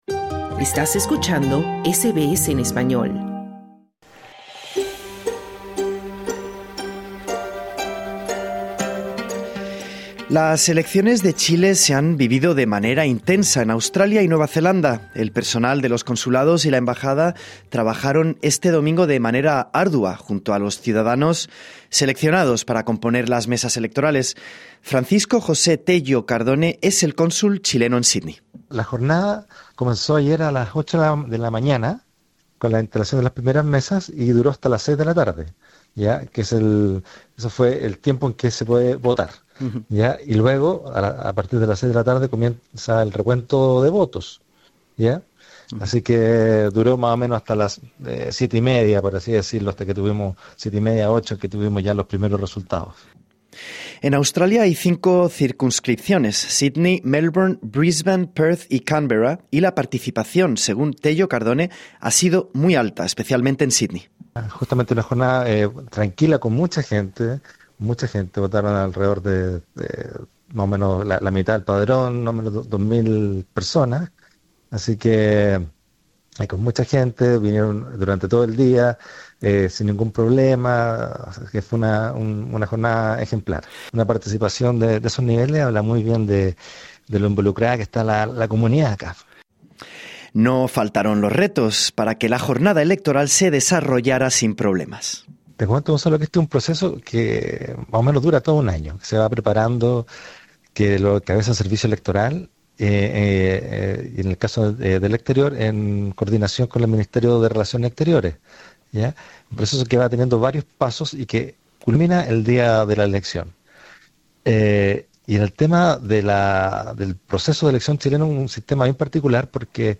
Las elecciones de Chile se han vivido de manera intensa en Australia y Nueva Zelanda. El cónsul general de Chile en Sídney, Francisco José Tello Cardone nos explica cómo fue la jornada electoral.